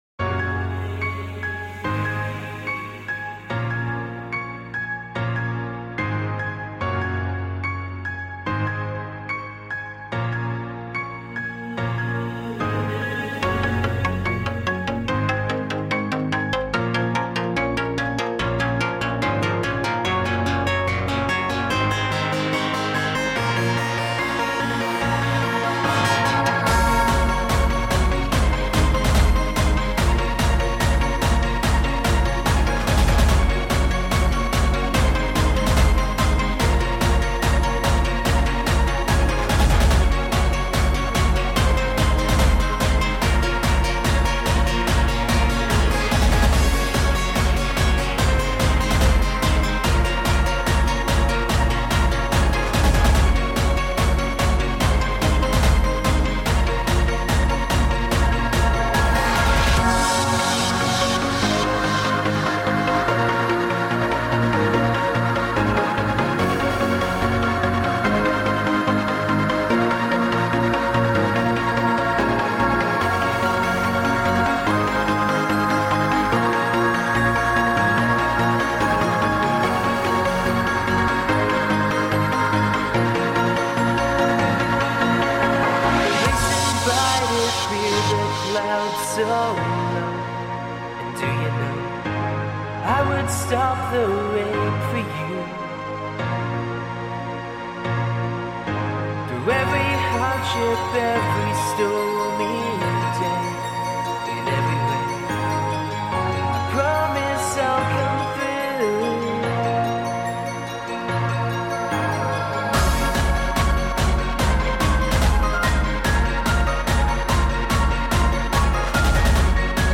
Finally did a track with vocals.